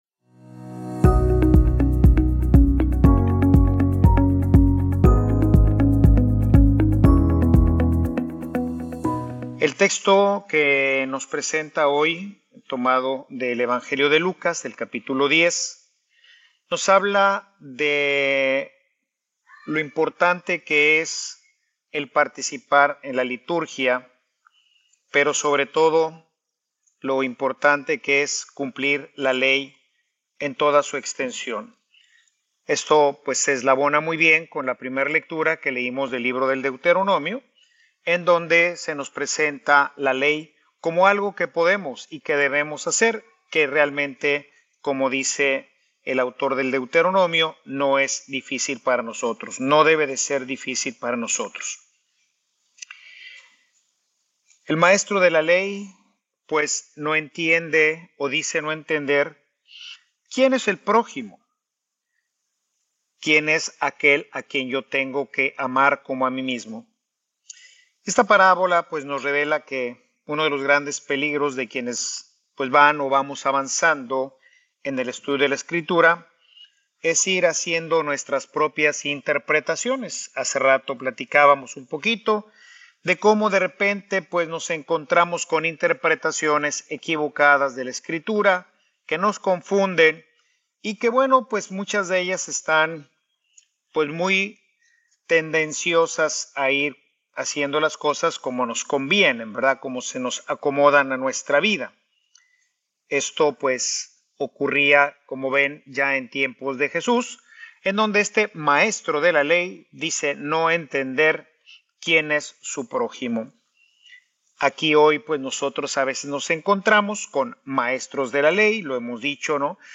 Homilia_Que_tengo_que_hacer_para_entrar_al_cielo.mp3